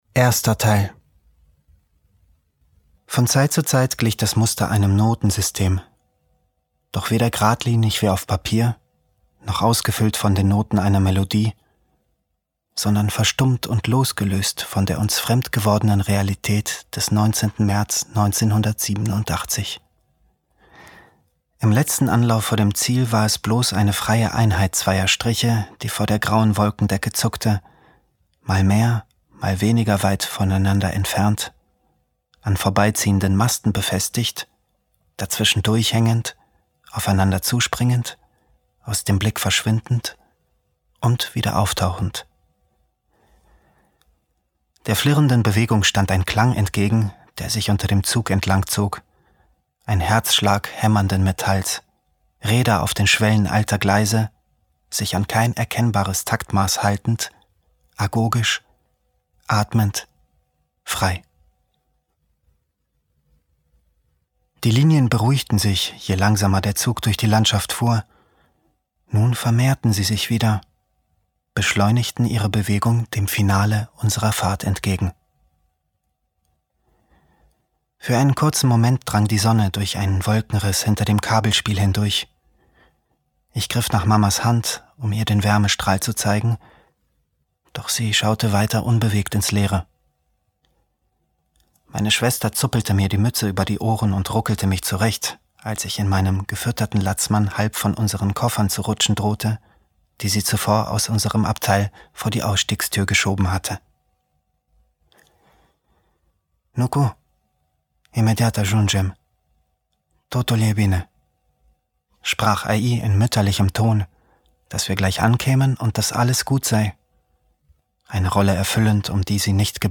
Sprecher Sabin Tambrea